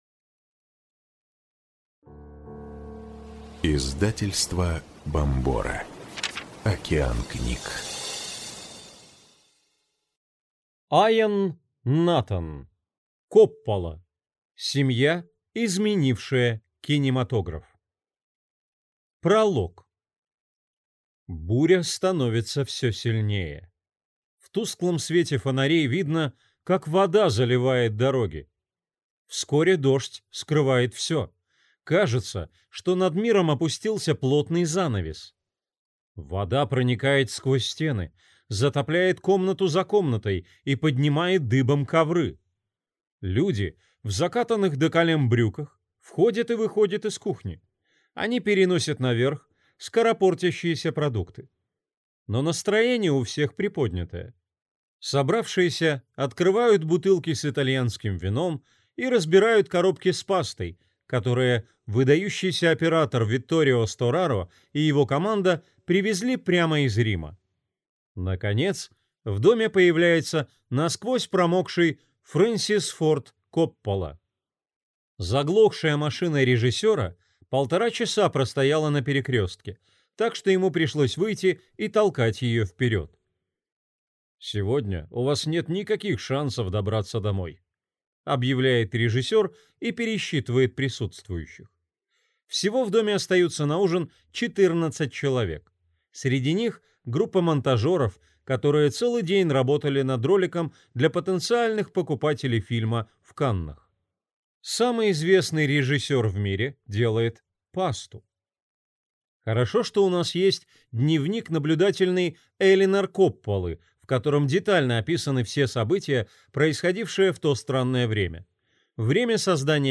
Аудиокнига Коппола. Семья, изменившая кинематограф | Библиотека аудиокниг